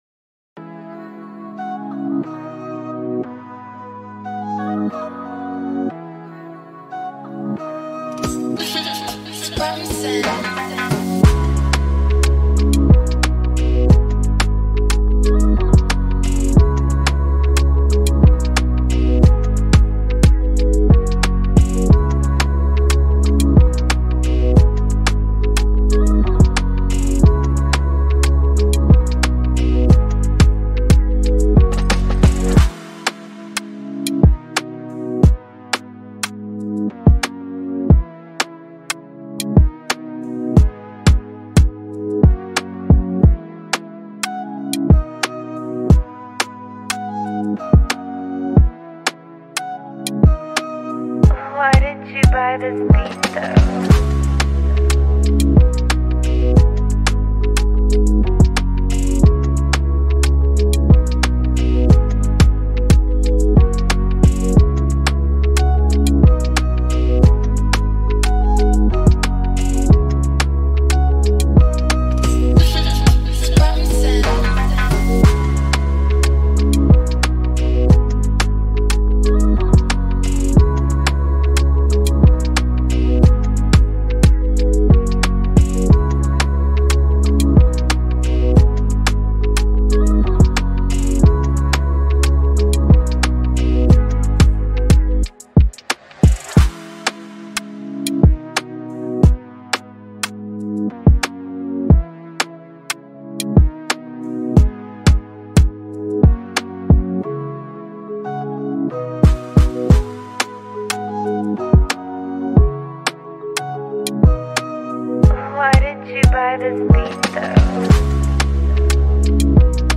blending infectious rhythms with smooth melodies.
groovy foundation